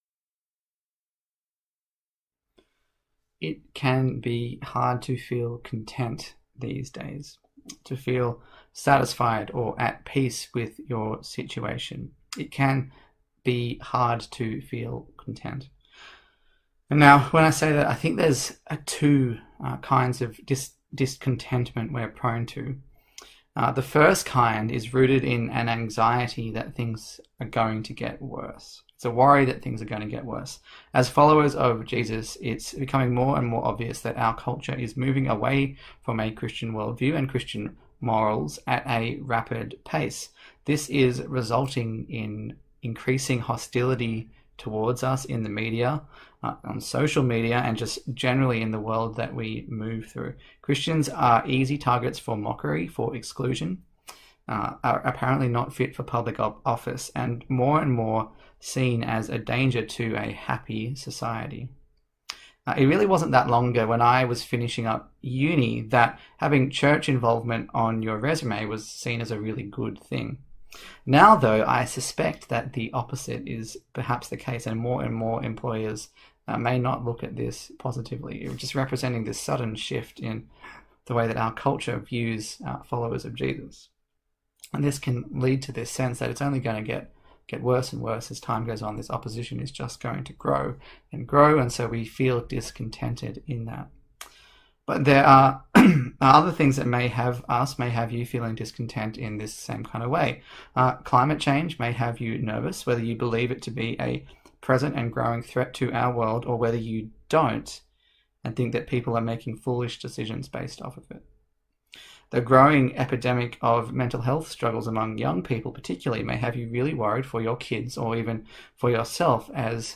Philippians Passage: Philippians 4:2-23 Service Type: Sunday Morning